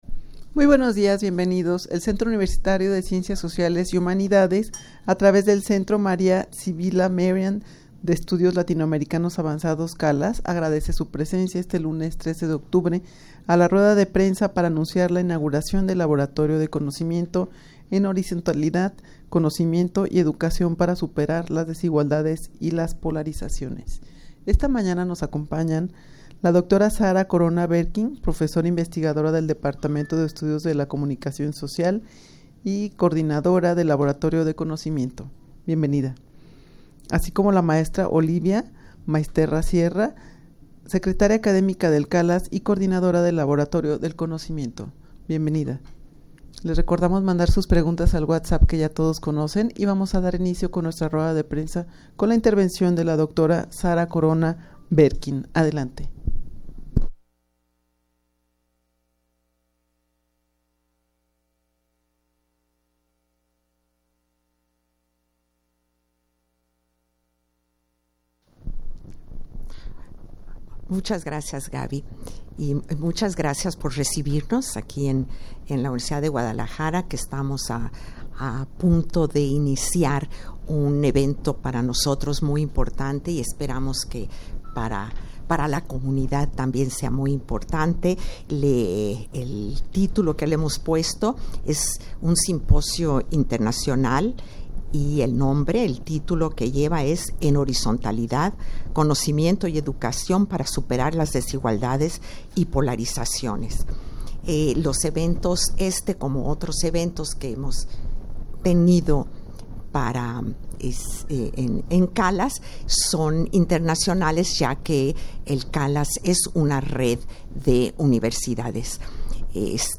Audio de la Rueda de Prensa
rueda-de-prensa-para-anunciar-la-inauguracion-del-laboratorio-de-conocimiento-en-horizontalidad.mp3